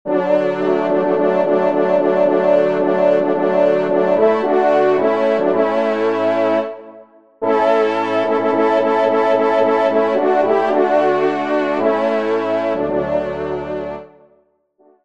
Genre : Musique Religieuse pour  Quatre Trompes ou Cors
ENSEMBLE